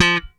F3 3 F.BASS.wav